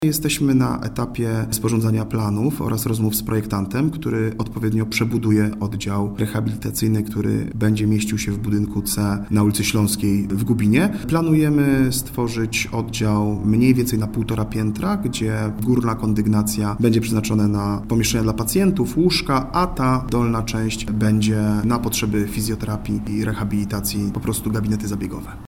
– Do zrobienia zostało nam jeszcze wiele, ale najważniejsze, że kształt oddział jest już znany – mówi Radosław Sujak, członek zarządu powiatu krośnieńskiego.